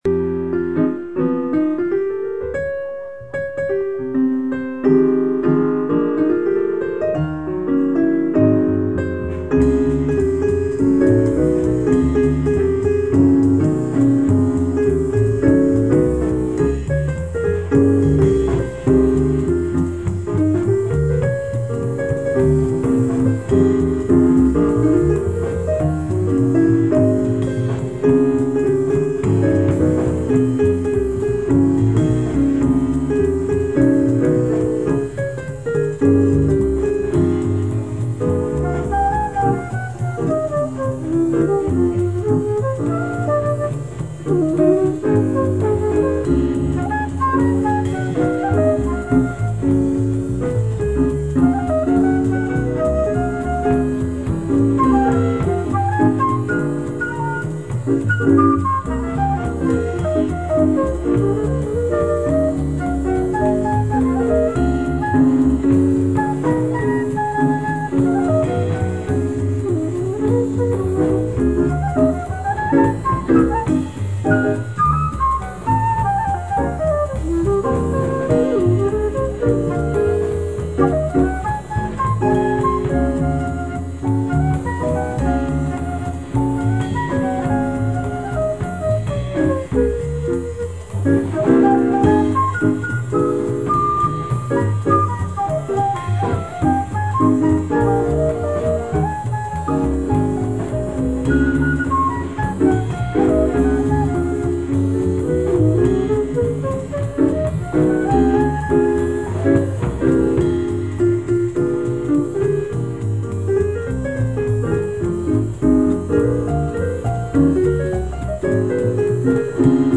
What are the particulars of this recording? Jazz Festival Sept. 15, 2006